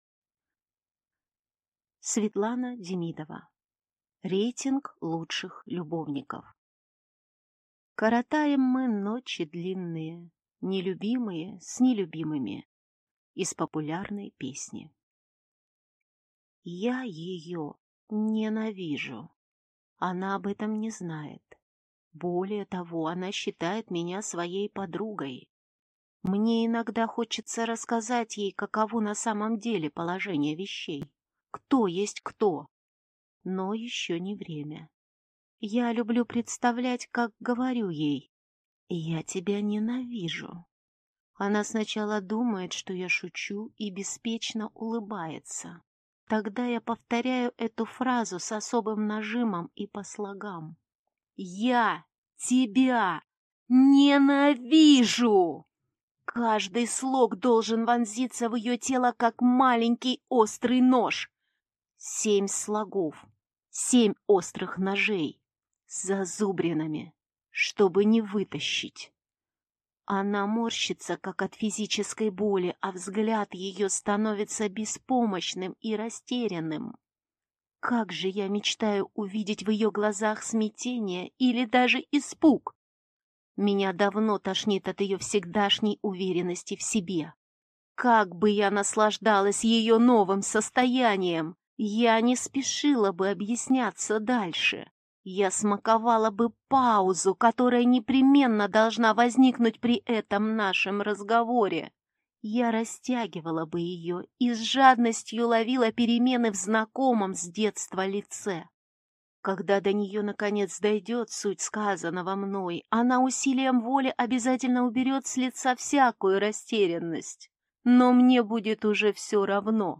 Аудиокнига Рейтинг лучших любовников | Библиотека аудиокниг
Прослушать и бесплатно скачать фрагмент аудиокниги